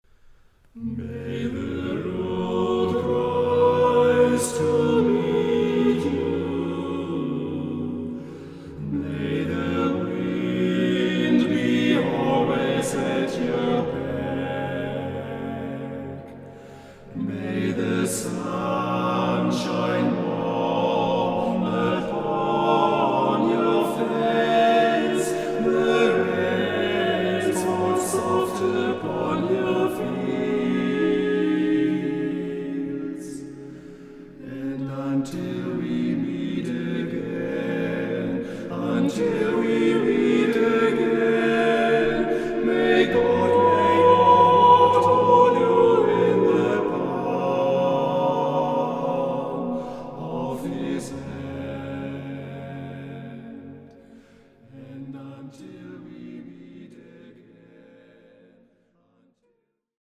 Shop / CDs / Vokal